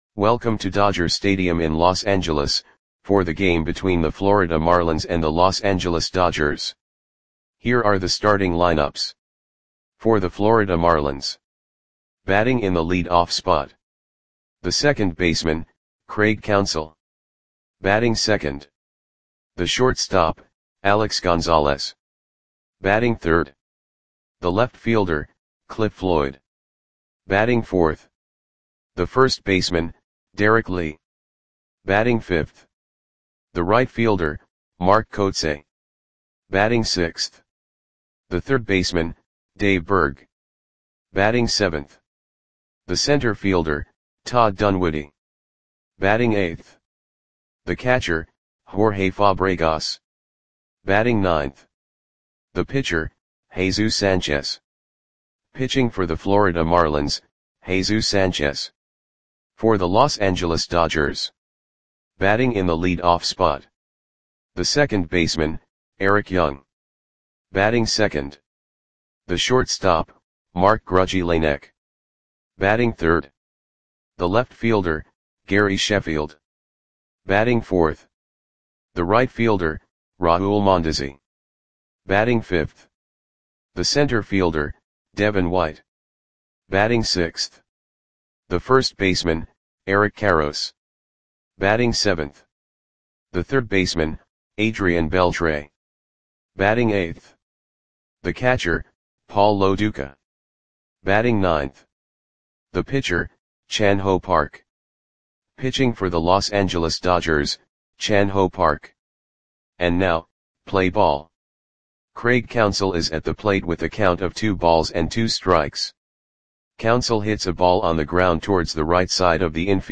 Audio Play-by-Play for Los Angeles Dodgers on May 9, 1999
Click the button below to listen to the audio play-by-play.